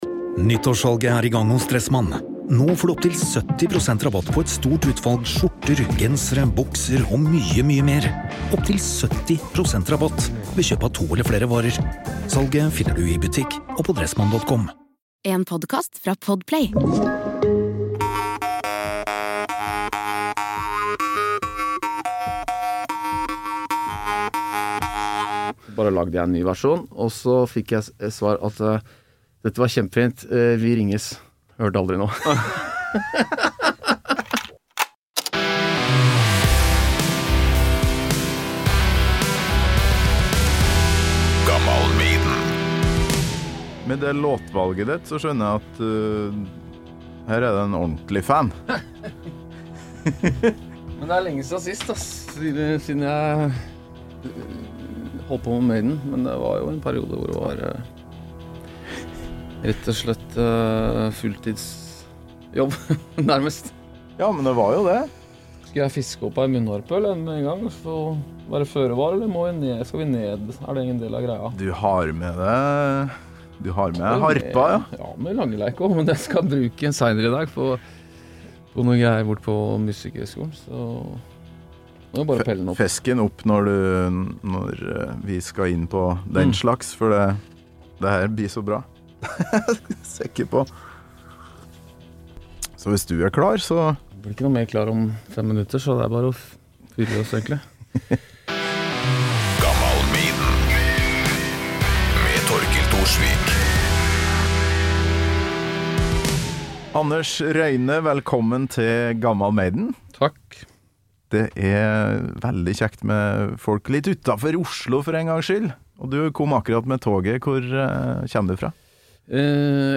Maiden på munnharpe og langeleik!